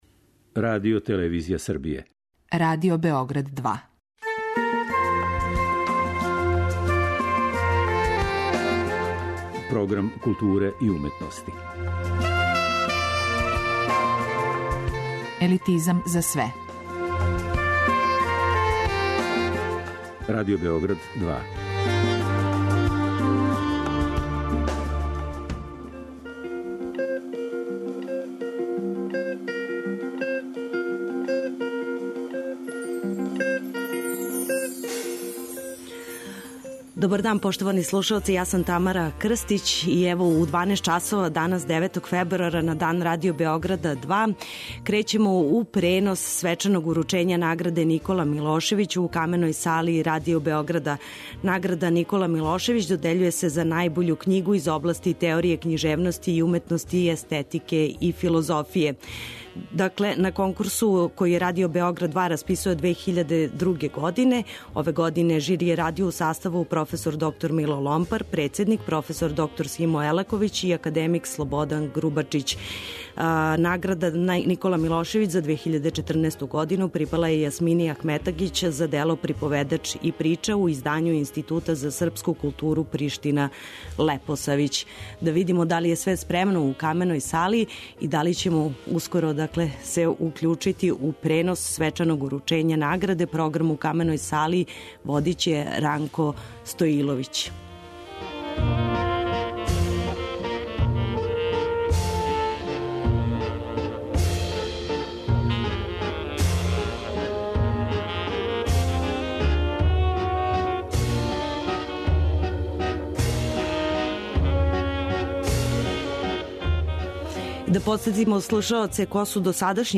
На Дан Радио Београда 2, из Камене сале Радио Београда директно преносимо доделу Награде 'Никола Милошевић' за најбољу књигу 2014. из области теорије књижевности и уметности, естетике и филозофије.
Директан пренос доделе наградe "Никола Милошевић"